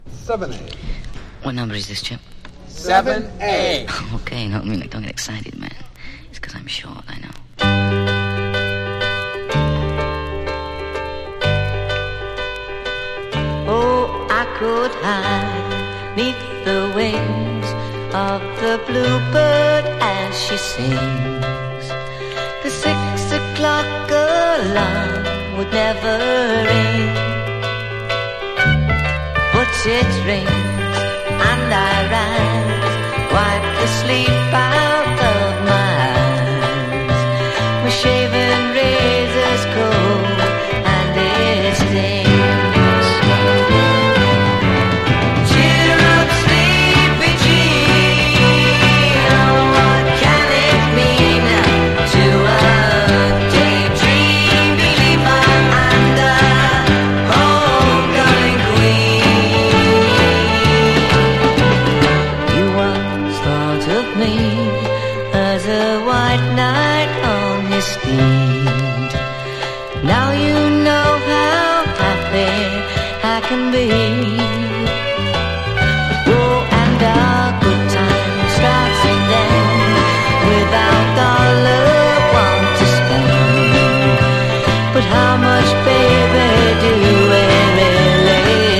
1. 60'S ROCK >